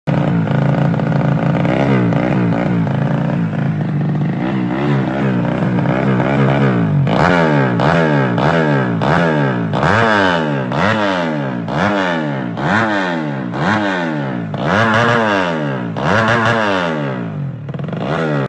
ceksound kenalpot slep Engin Ex